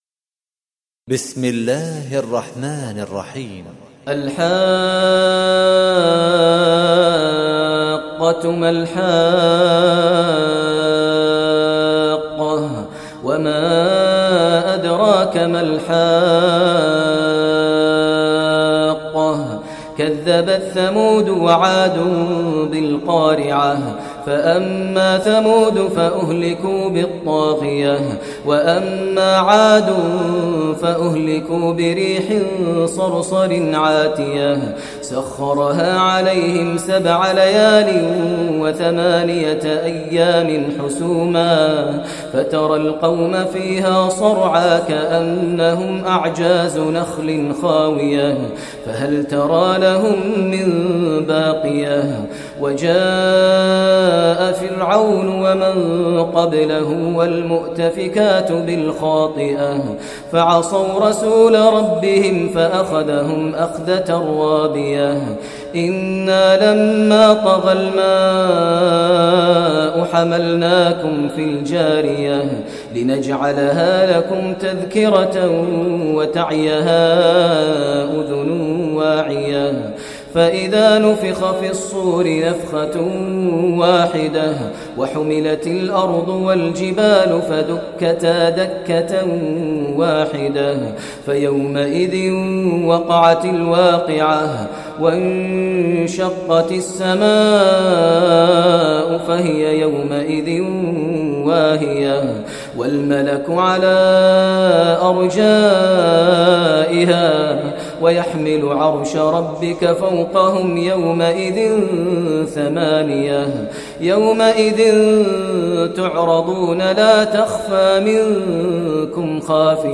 Surah Haqqah Recitation by Sheikh Maher Mueaqly
Surah Haqqah, listen online mp3 tilawat / recitation in Arabic in the voice of Imam e Kaaba Sheikh Maher al Mueaqly.